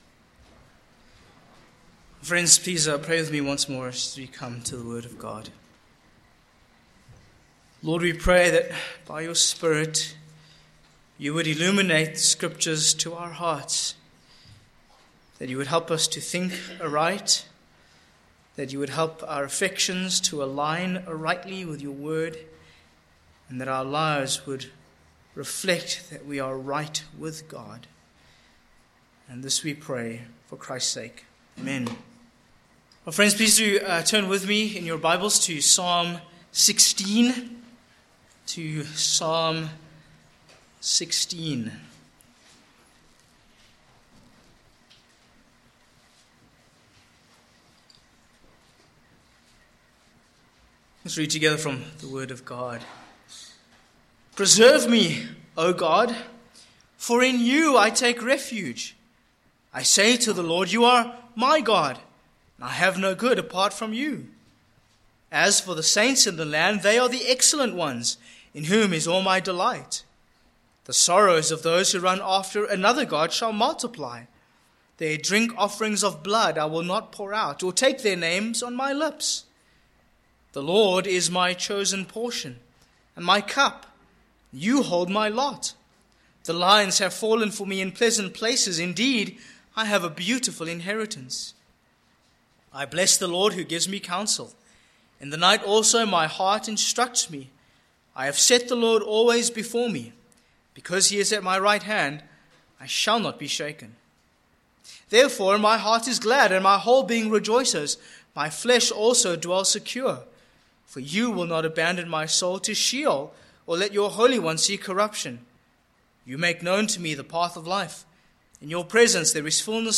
Sermon points: 1. A Prayer for Preservation v1-4